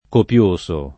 [ kop L1S o ]